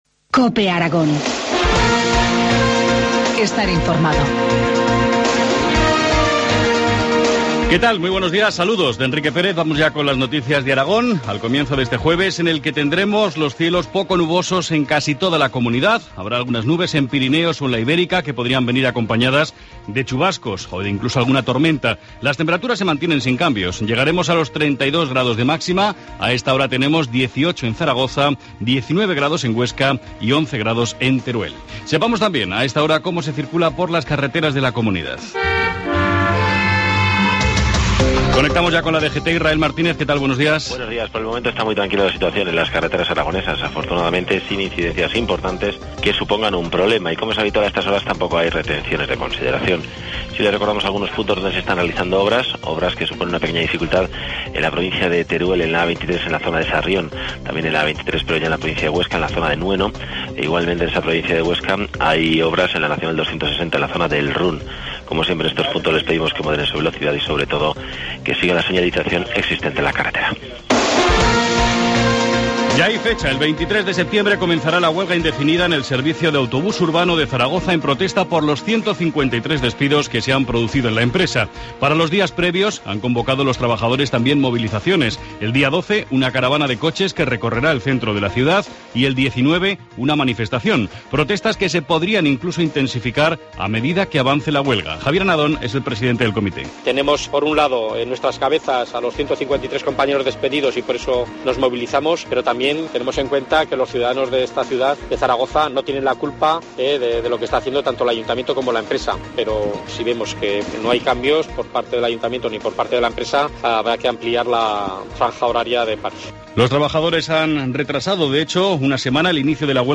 Informativo matinal, jueves 5 de septiembre, 7.25 horas